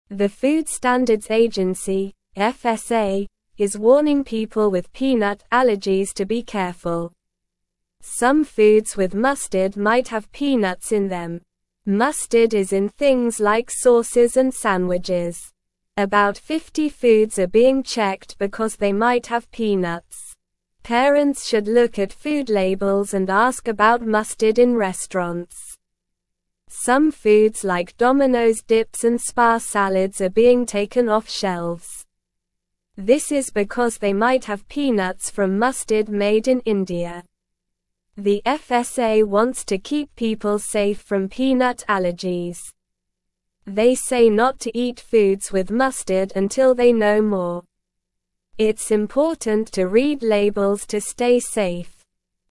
Slow
English-Newsroom-Beginner-SLOW-Reading-Warning-for-Peanut-Allergies-Check-Foods-with-Mustard.mp3